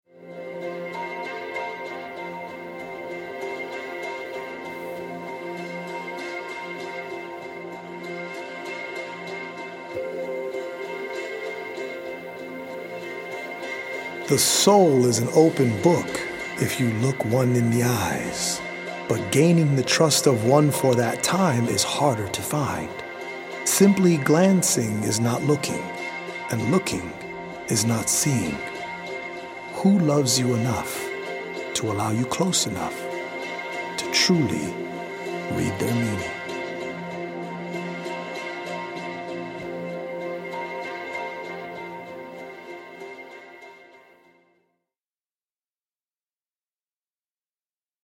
audio-visual poetic journey
healing Solfeggio frequency music